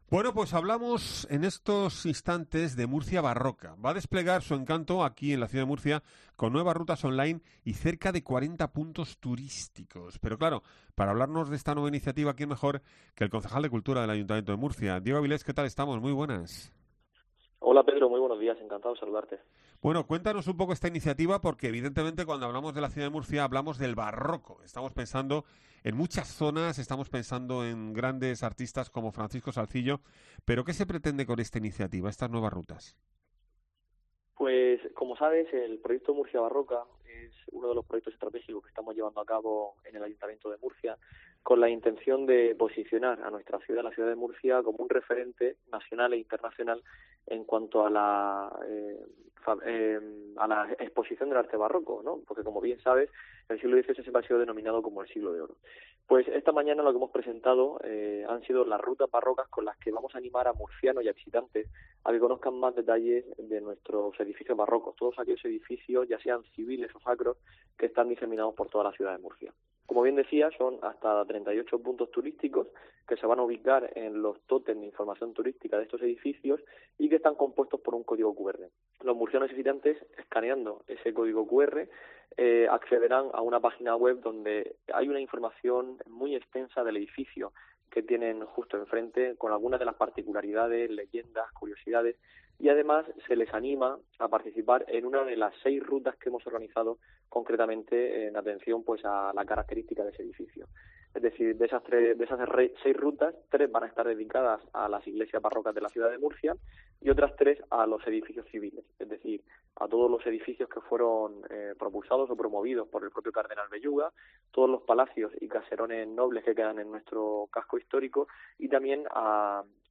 Diego Avilés, concejal de Cultura e Identidad